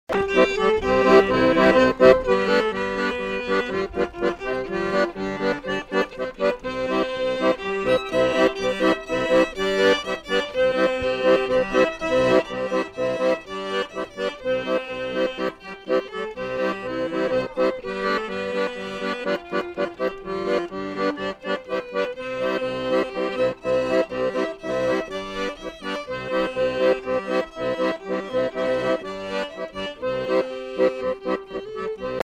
Aire culturelle : Savès
Lieu : Sauveterre
Genre : morceau instrumental
Instrument de musique : violon ; accordéon diatonique
Danse : polka